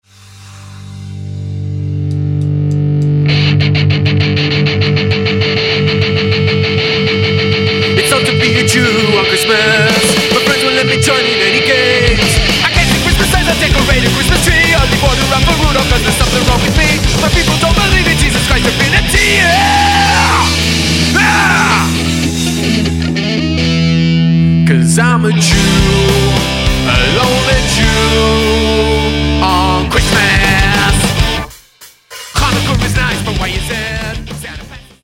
A very cool, in-your-face quick, thrashy punk EP.